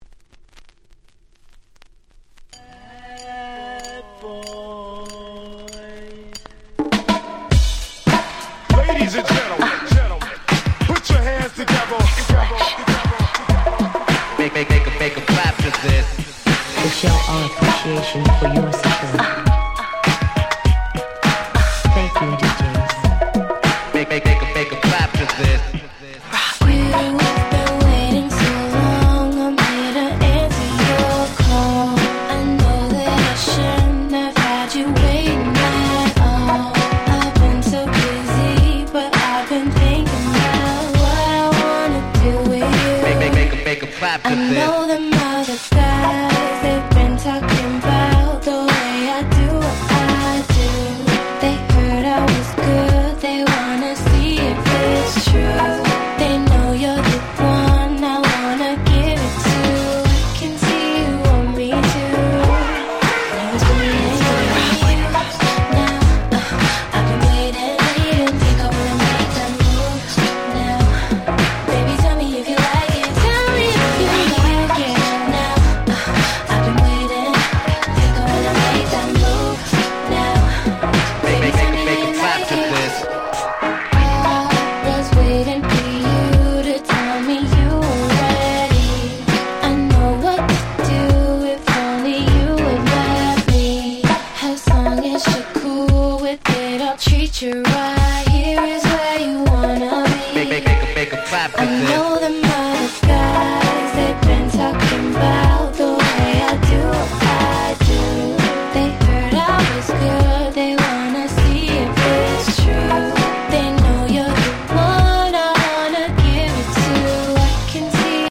06' Very Nice R&B Remix !!